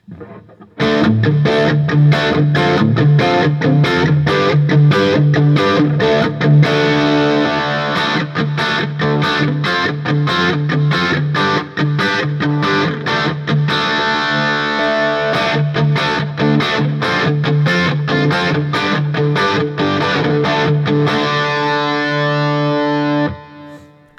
D-Shape Chords
As usual, for these recordings I used my normal Axe-FX Ultra setup through the QSC K12 speaker recorded into my trusty Olympus LS-10.
For each recording I cycled through the neck pickup, both pickups, and finally the bridge pickup.